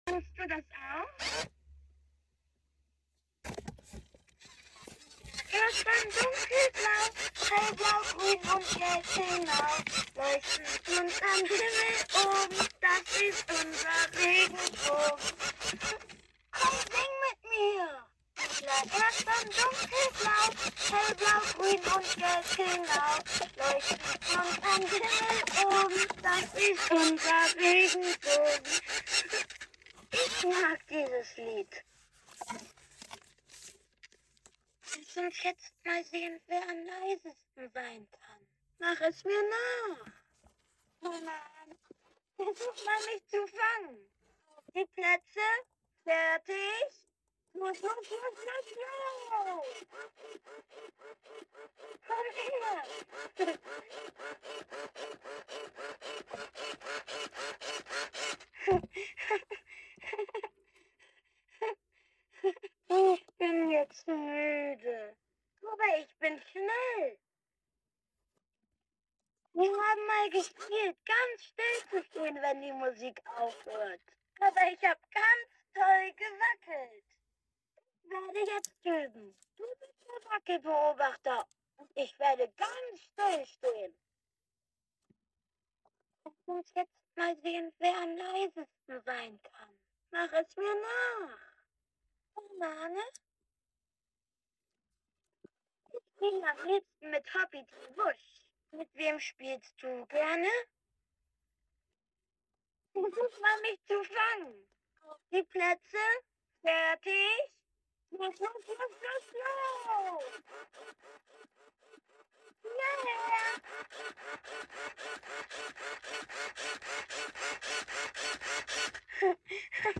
Bing Plush speaks German Kuscheltier sound effects free download